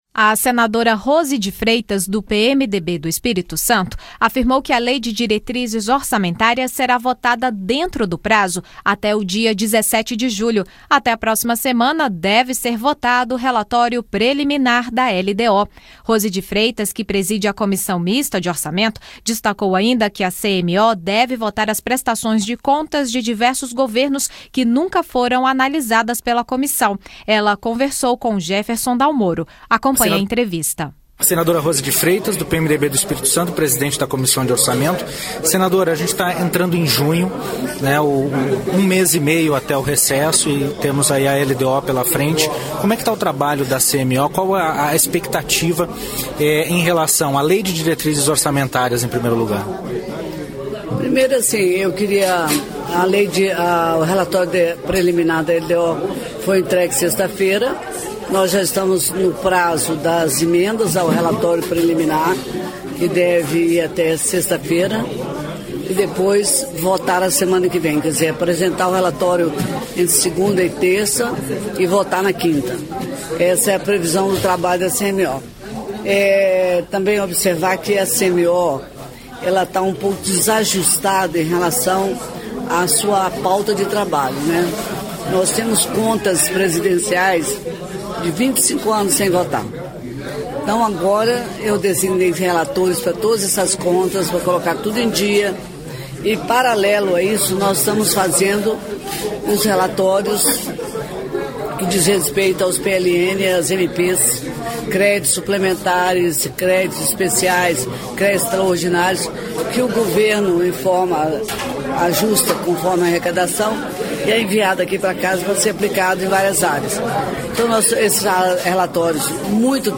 Notícias e entrevistas sobre os principais temas discutidos durante a semana no Senado Federal